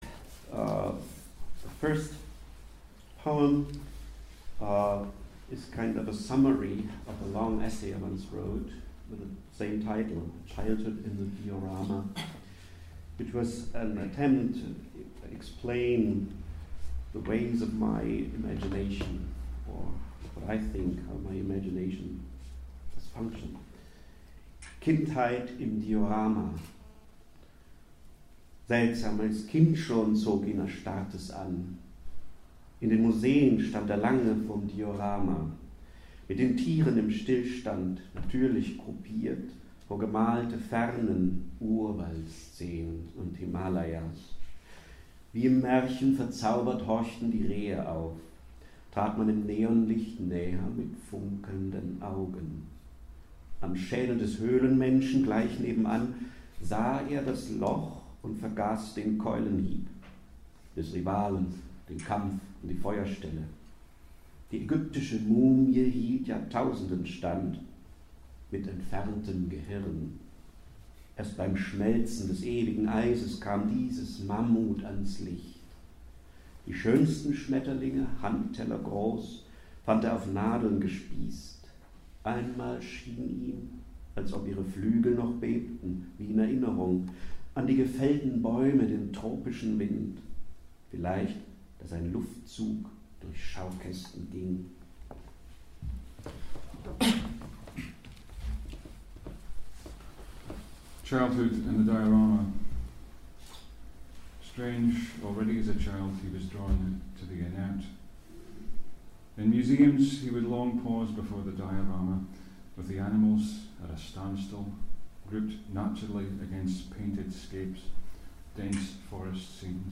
'Kindheit im Diorama' by Durs Gruenbein with a translation in English read by Don Paterson